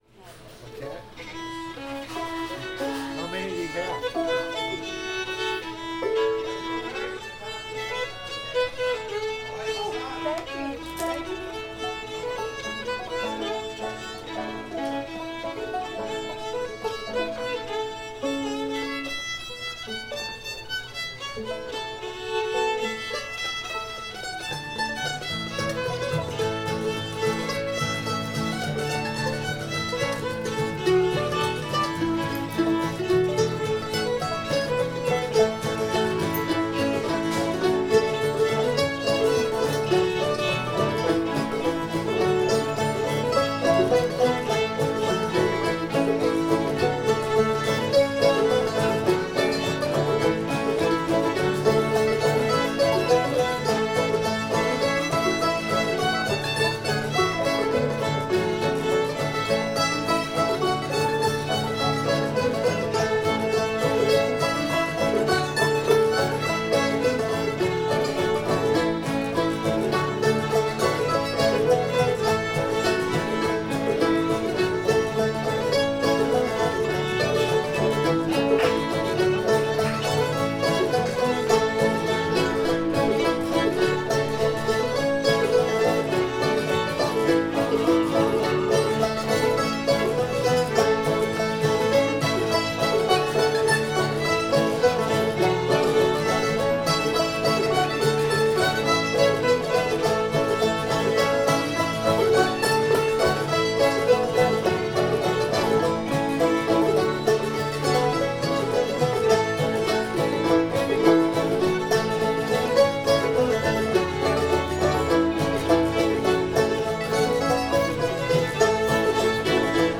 booth [A]